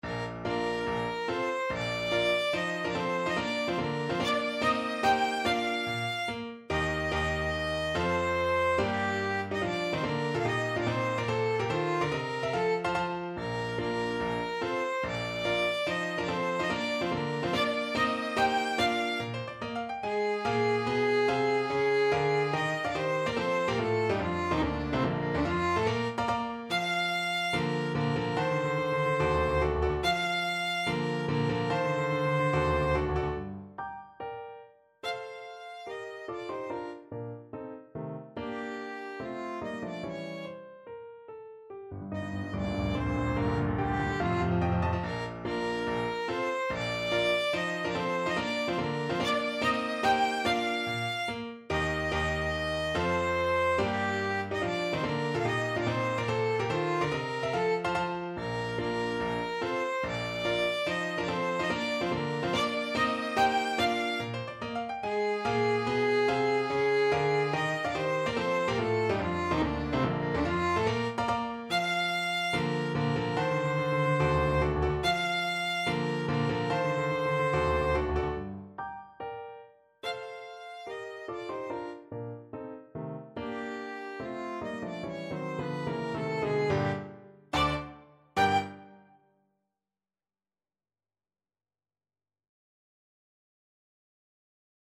Classical Berlioz, Hector March to the Scaffold from Symphonie Fantastique (Excerpt) Violin version
Violin
4/4 (View more 4/4 Music)
Bb major (Sounding Pitch) (View more Bb major Music for Violin )
Allegro non troppo (=72) (View more music marked Allegro)
Classical (View more Classical Violin Music)